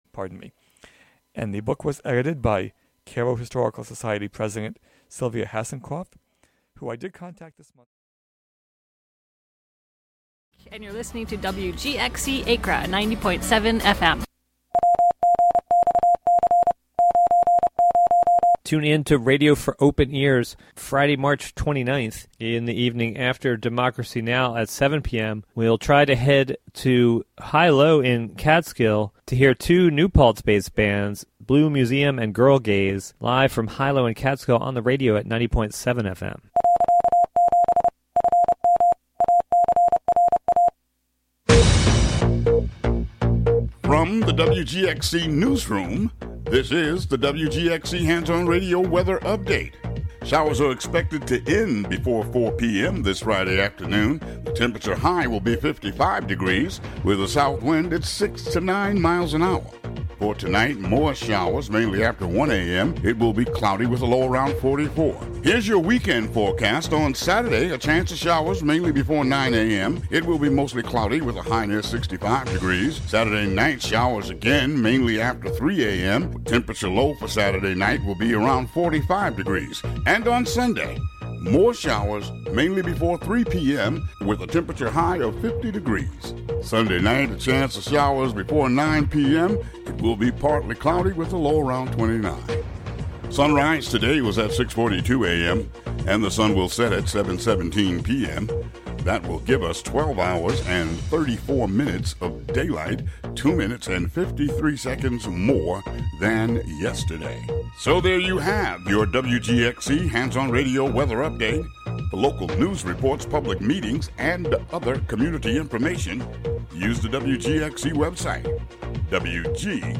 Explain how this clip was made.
Broadcast live from the Hudson studio.